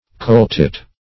Search Result for " coletit" : The Collaborative International Dictionary of English v.0.48: Coletit \Cole"tit`\ or Coaltit \Coal"tit\, n. (Zool.)